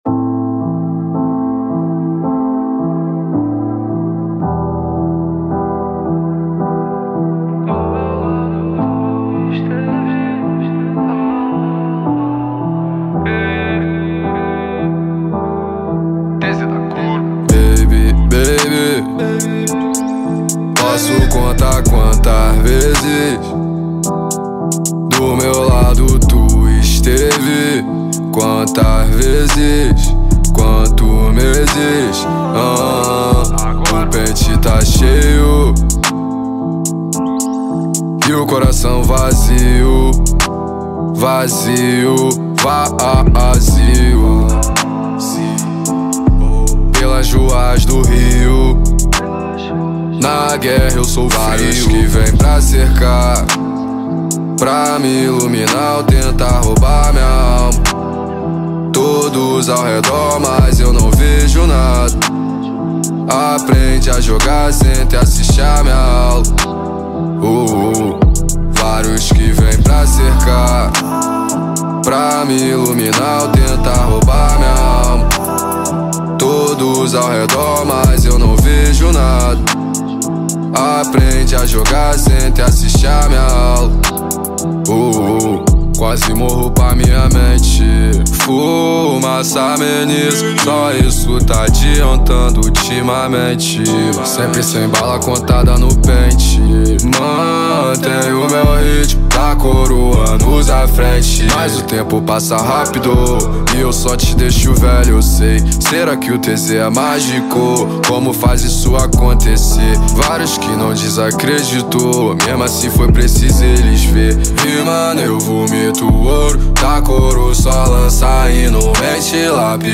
2024-07-21 12:09:42 Gênero: Funk Views